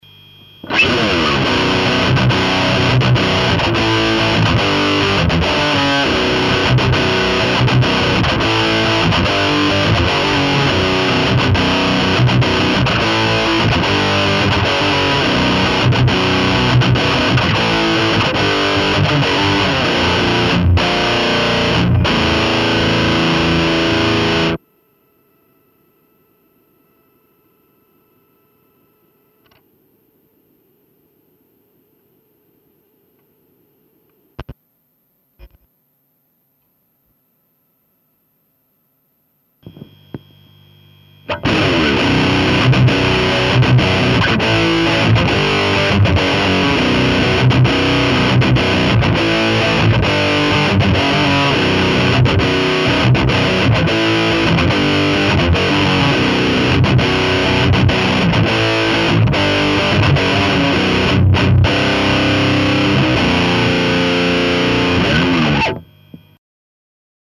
Le grain de la R1 est vraiment TRES proche du preamp du rectifier (je peux faire un test comparatif vu que j'ai les deux).
donc, oui, il y a des (très légères) différences liées aux Eq qui sont différentes sur la R1 et le Mesa, mais je trouve avec mes humbles oreilles que le grain et la dynamique sont identiques sur les deux préamps (et en tout cas, indiscernables dans le cas d'un mix ou du jeu en groupe).
AMT R1 vs Mesa Boogie Rectifier Recording Preamp.mp3